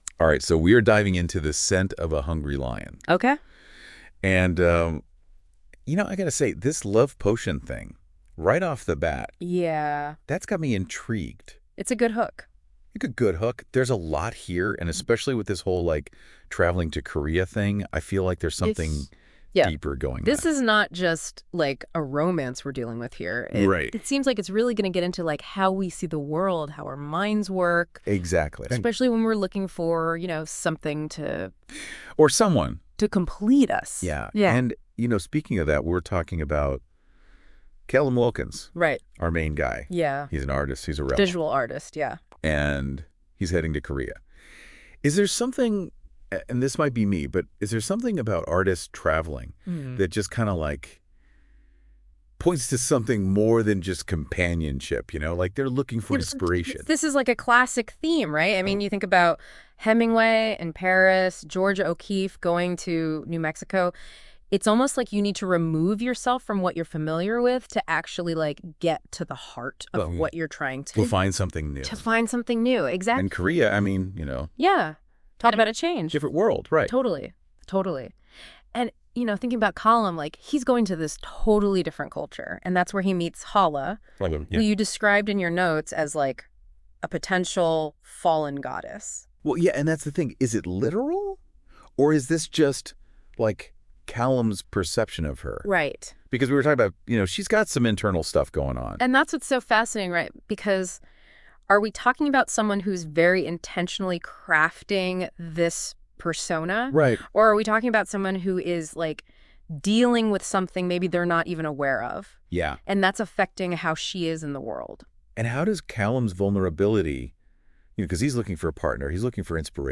Join the lively discussion as the radio hosts delve into the key themes and captivating storylines of the book.